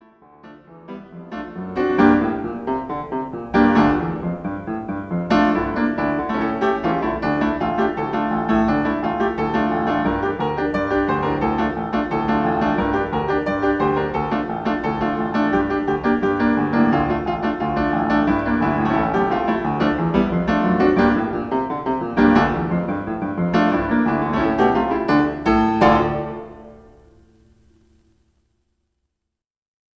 an album full of instrumental compositions and arrangements
Enjoy Jewish musical sounds from around the world.